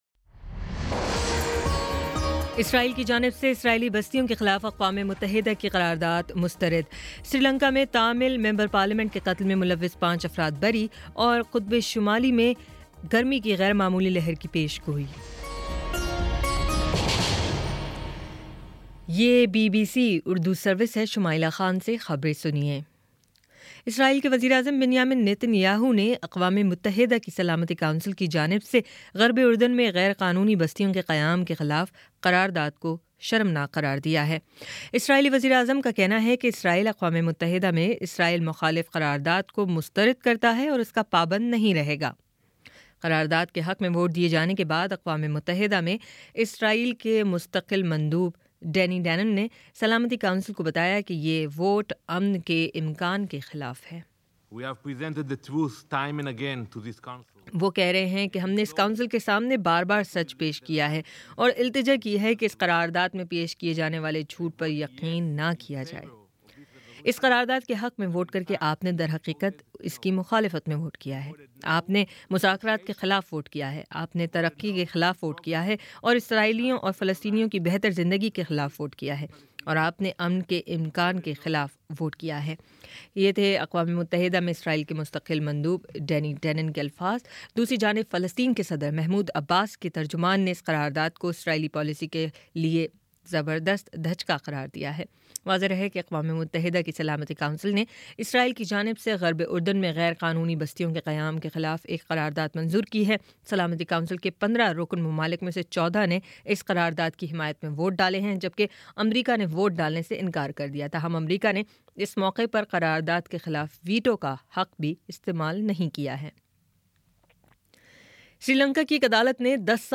دسمبر 24 : شام چھ بجے کا نیوز بُلیٹن